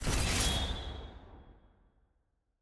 sfx_ui_research_military.ogg